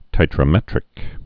(tītrə-mĕtrĭk)